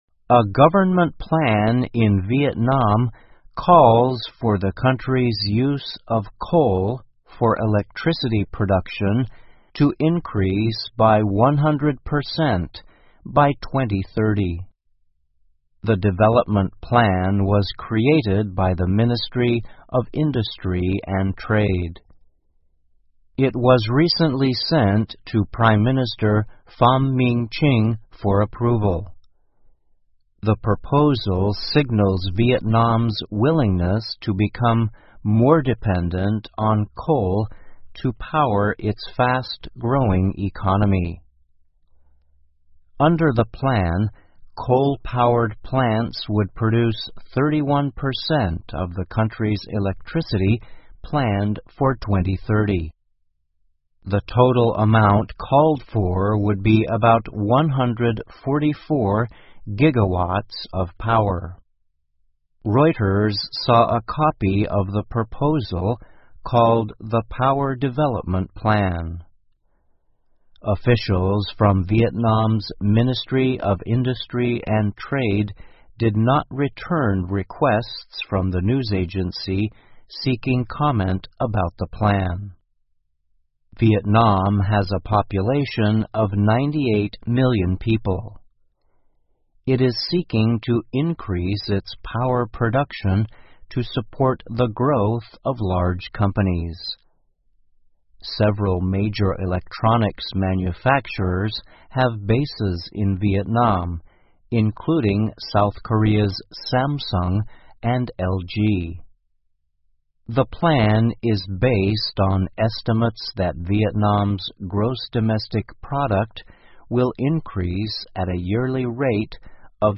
VOA慢速英语2021 越南越来越依赖燃煤发电 听力文件下载—在线英语听力室